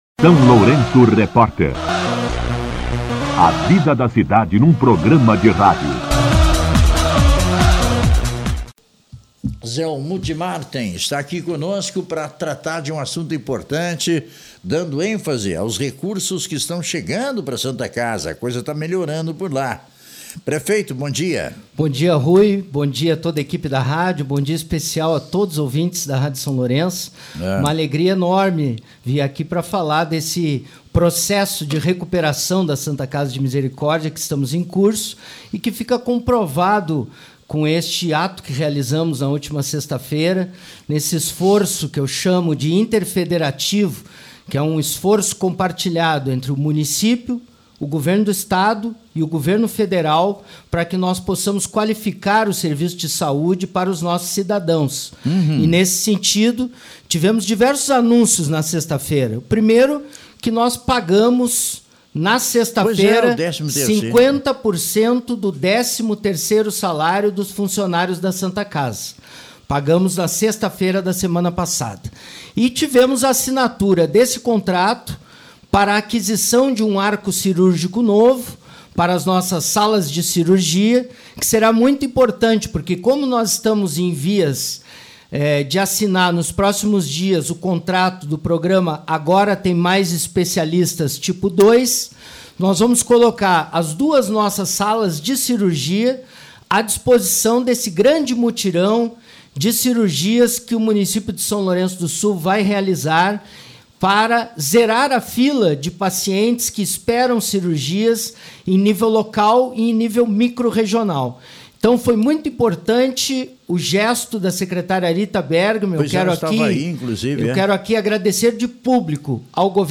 Entrevista com o Prefeito Zelmute Marten
Em entrevista à SLR RÁDIO nesta segunda-feira, o prefeito Zelmute Marten destacou a importância do investimento e anunciou também o pagamento de 50% do 13º salário aos funcionários da instituição.